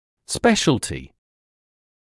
[‘speʃəltɪ][‘спэшэлти]специальной (гл.об. в медицине)